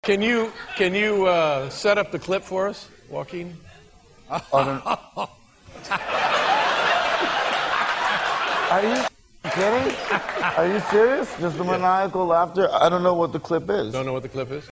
Tags: Joaquin Phoenix Joaquin Phoenix on Drugs Joaquin Phoenix on David Letterman Joaquin Phoenix interview funny clip